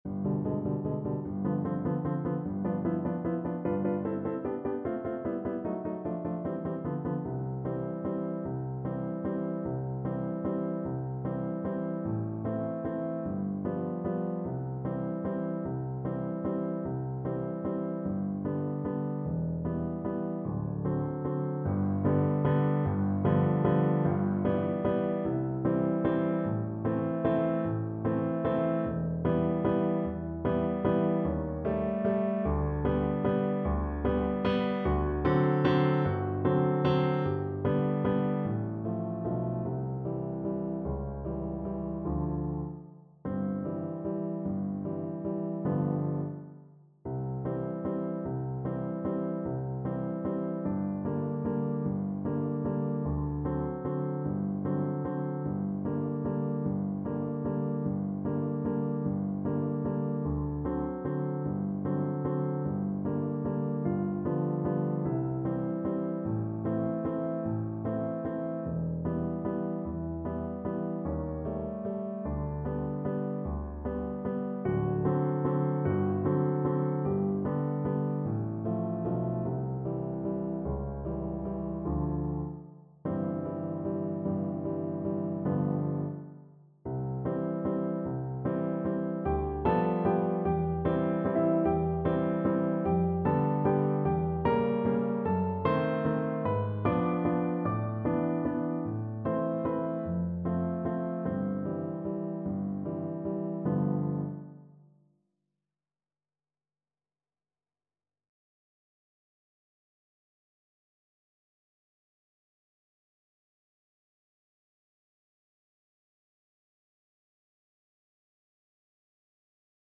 Cello
3/4 (View more 3/4 Music)
C major (Sounding Pitch) (View more C major Music for Cello )
Adagio =50
Classical (View more Classical Cello Music)